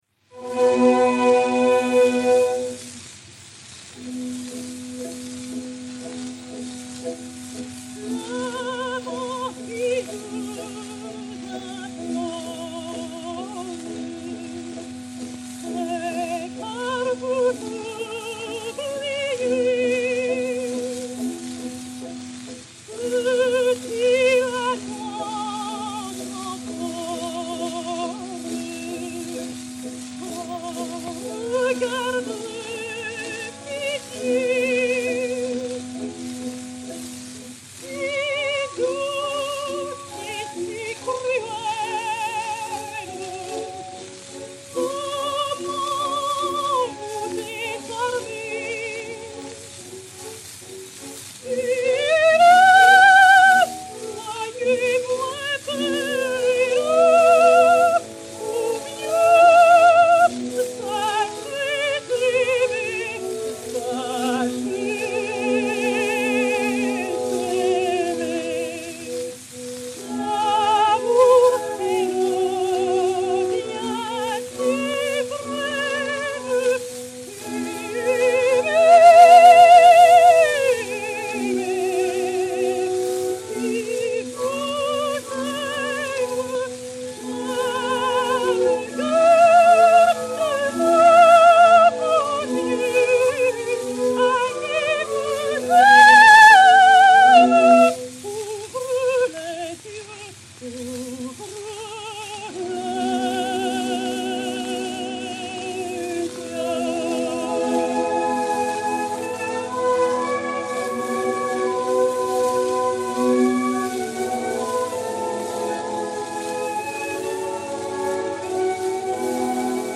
Berthe Auguez de Montalant (Marquise de Bryane) et Orchestre
Disque Pour Gramophone 033067, mat. 855i, enr. à Paris en octobre 1908